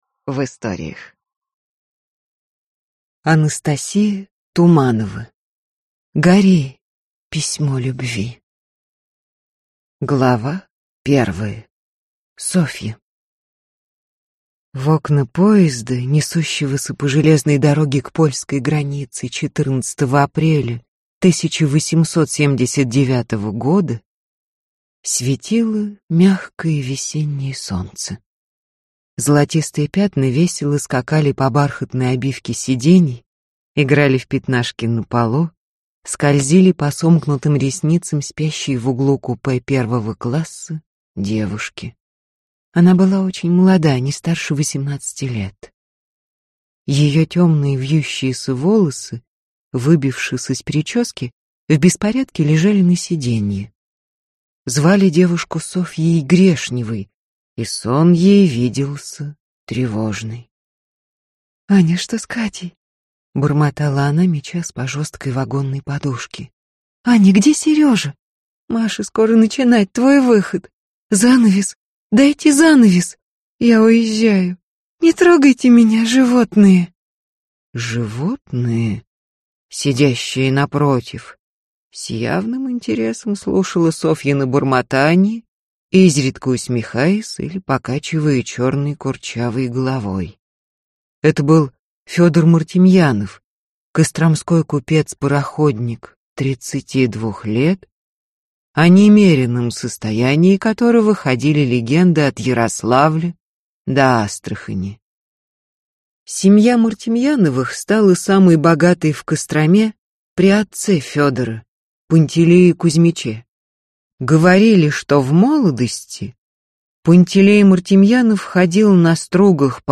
Аудиокнига Гори, письмо любви | Библиотека аудиокниг